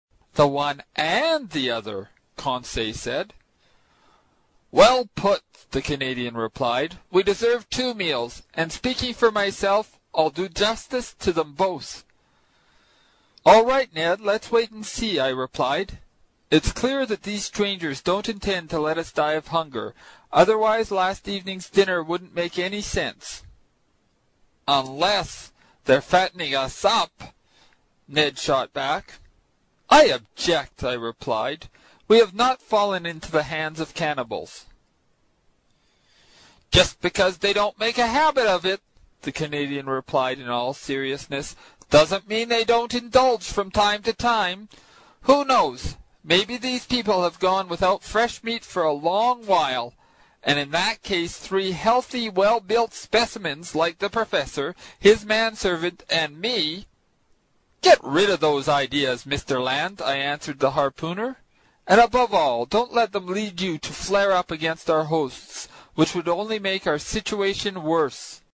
英语听书《海底两万里》第121期 第9章 尼德·兰的愤怒(6) 听力文件下载—在线英语听力室
在线英语听力室英语听书《海底两万里》第121期 第9章 尼德·兰的愤怒(6)的听力文件下载,《海底两万里》中英双语有声读物附MP3下载